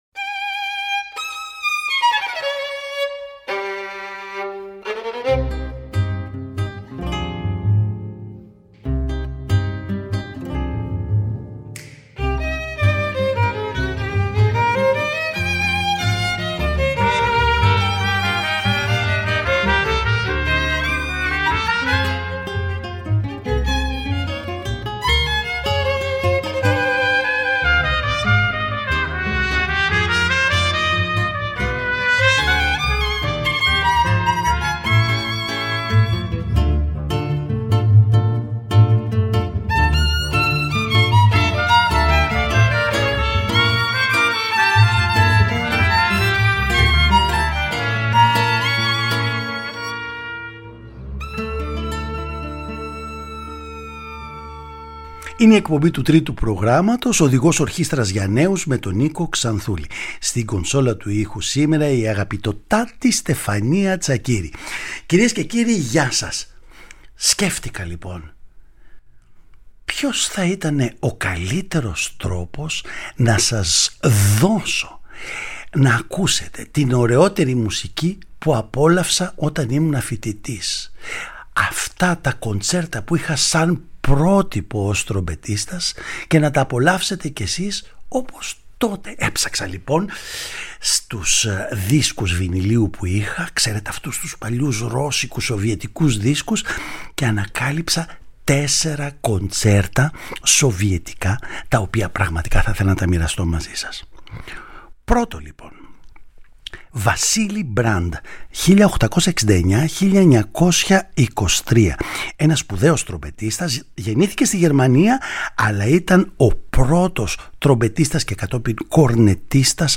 Η εκπομπή μας κάνει ένα αφιέρωμα σε σοβιετικά κοντσέρτα για τρομπέτα με σολίστ τον σπουδαίο τρομπετίστα Τιμοφέι Ντοκσίτσερ. Δύο Ρωσο-Γερμανοί (W. Brandt και O. Boehme), ένας Αρμένιος (Α. Αρουτουνιάν) και ένας Ρώσος (Ι. Σάχοφ) είναι οι συνθέτες των οποίων τα κοντσέρτα για τρομπέτα παρουσιάζονται στην εκπομπή μας και σας καλώ να τα απολαύσετε!
Παραγωγή-Παρουσίαση: Νίκος Ξανθούλης